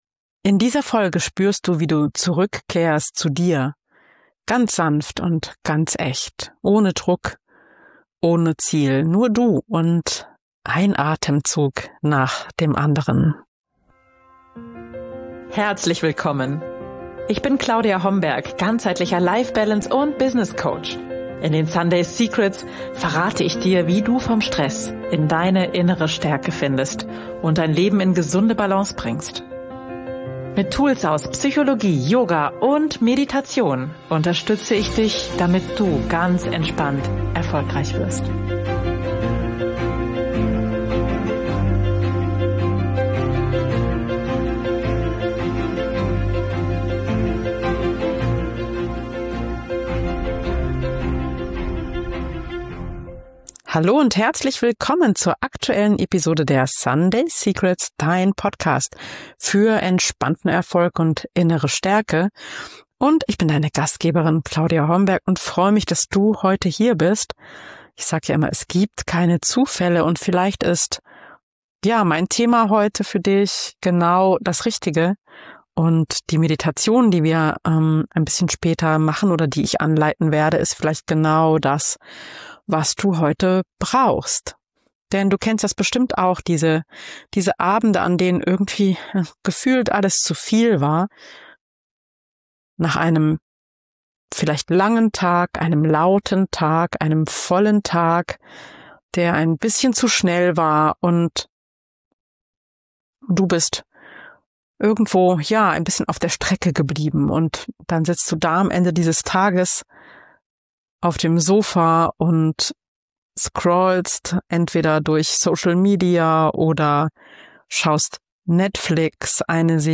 Eine geführte Meditation für all die Abende, an denen Du nur noch funktionierst – und Dich selbst kaum noch spürst.
Diese Meditation ist für Dich, wenn • Du müde bist – nicht nur vom Tag, sondern vom Funktionieren • Du Dich selbst irgendwo verloren hast • Du wieder fühlen willst, wer Du eigentlich bist Ich begleite Dich Schritt für Schritt zurück in Deine Präsenz. Ganz weich. Ganz still.